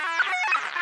radio_random13.ogg